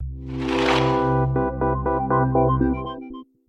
Index of /phonetones/unzipped/LG/LN272-Rumor-Reflex (ic)/Power Up-Down
LG_Shutdown2.mp3